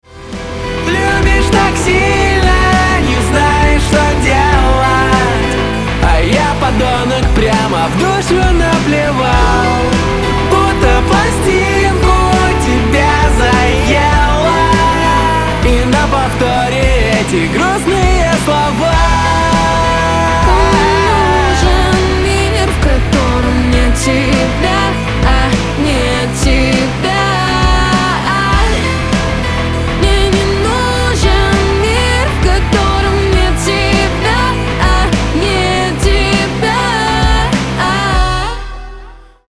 • Качество: 320, Stereo
мужской голос
дуэт
красивый женский голос